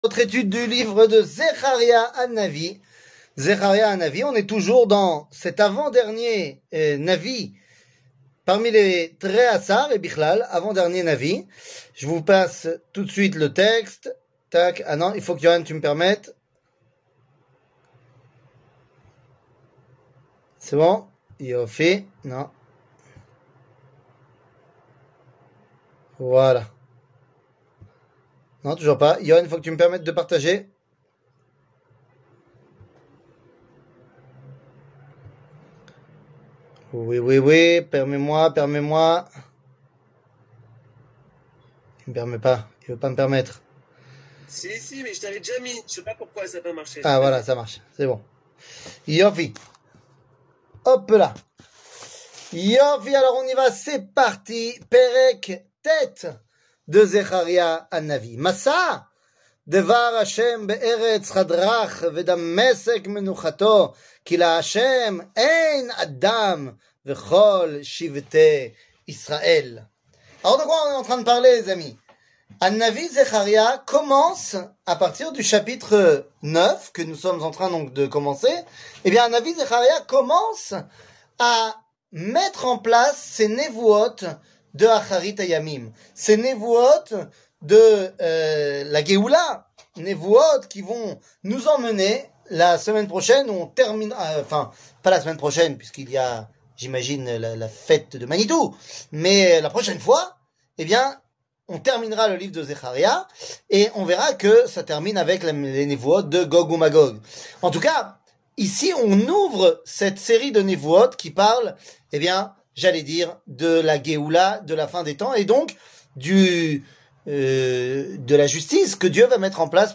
Les petits prophètes, 28, Zeharia (suite 4) 00:46:51 Les petits prophètes, 28, Zeharia (suite 4) שיעור מ 21 יוני 2022 46MIN הורדה בקובץ אודיו MP3 (42.88 Mo) הורדה בקובץ וידאו MP4 (85.09 Mo) TAGS : שיעורים קצרים